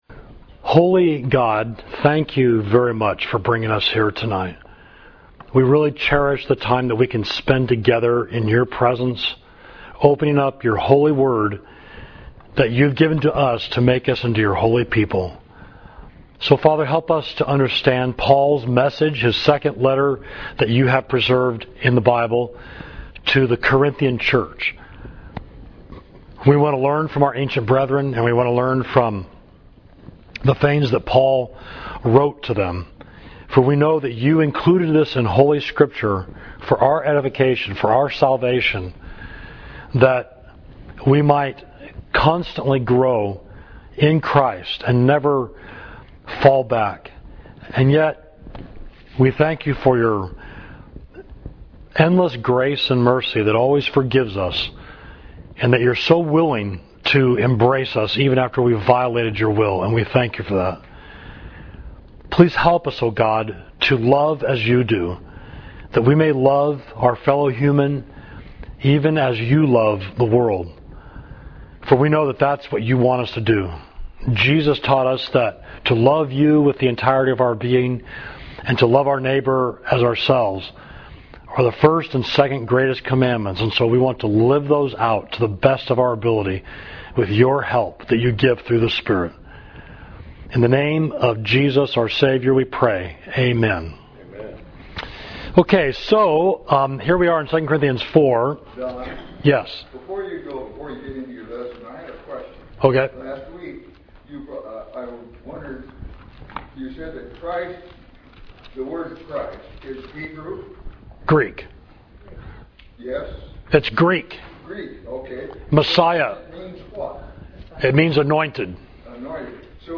Class: Preaching the Gospel, Second Corinthians 4.1–15